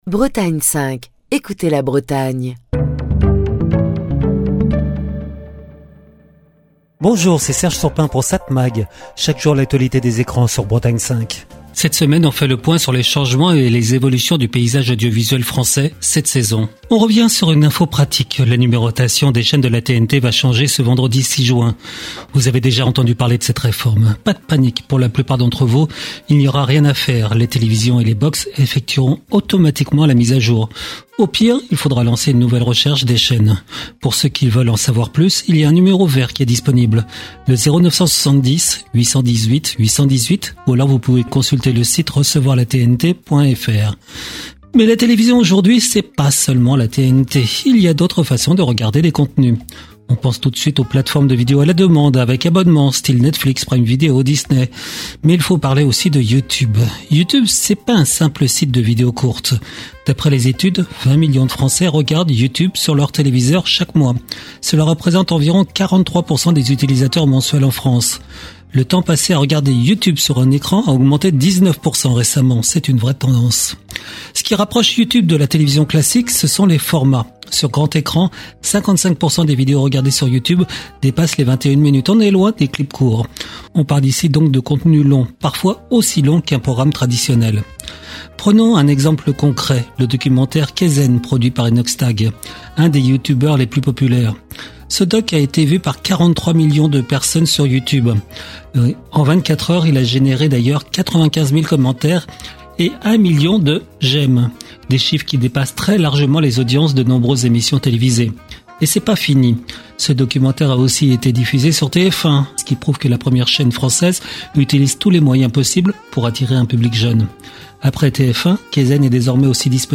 Chronique du 3 juin 2025.